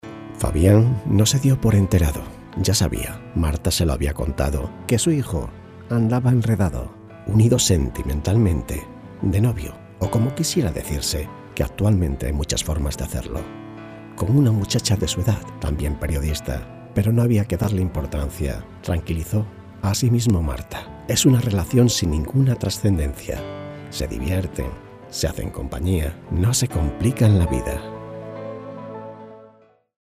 Locutores profesionales para la grabación de podcast.